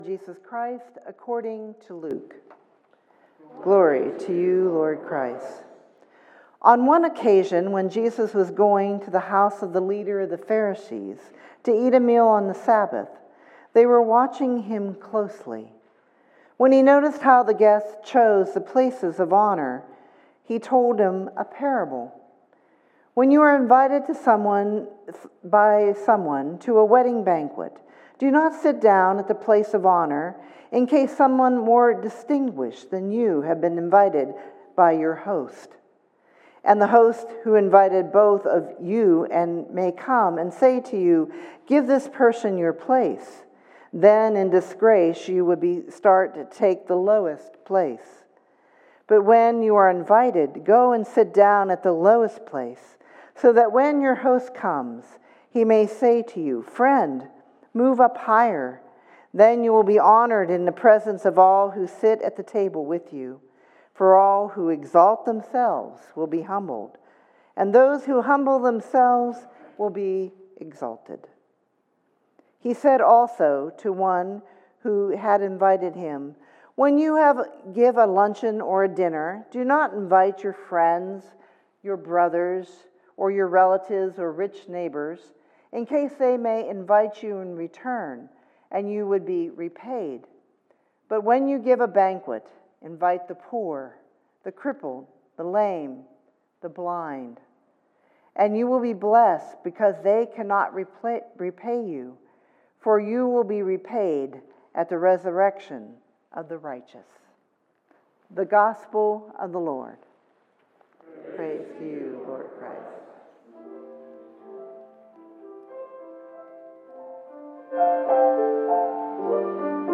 Twelfth Sunday after Pentecost, Luke 14:1,7-14